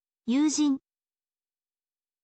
yuujin